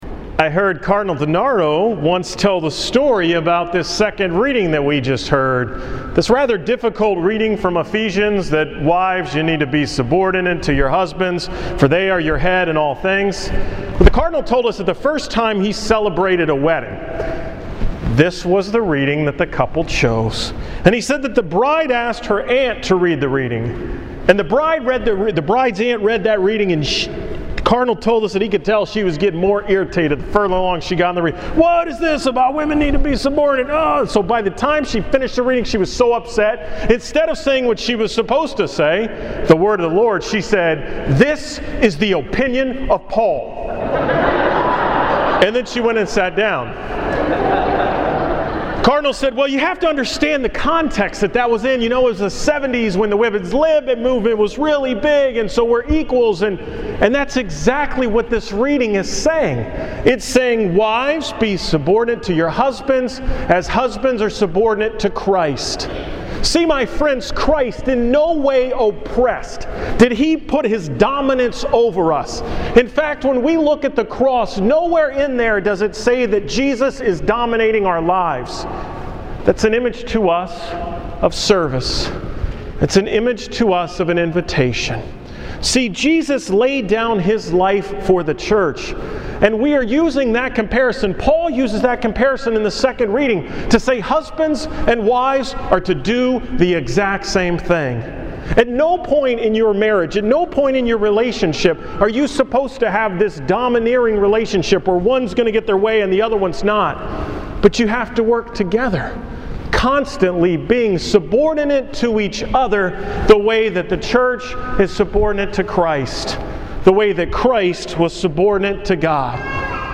Homily from August 26, 2012
From Sunday, August 26th at the 5 pm Mass.